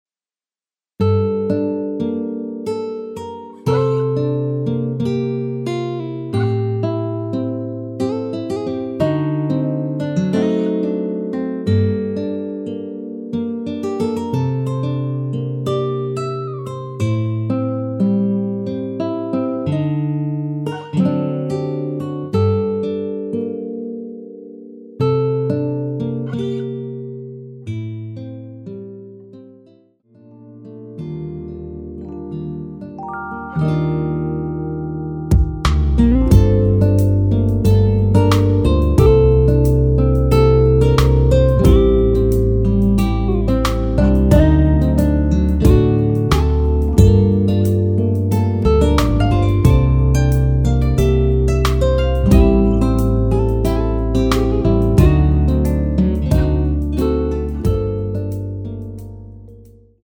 Gm
음정은 반음정씩 변하게 되며 노래방도 마찬가지로 반음정씩 변하게 됩니다.
앞부분30초, 뒷부분30초씩 편집해서 올려 드리고 있습니다.
중간에 음이 끈어지고 다시 나오는 이유는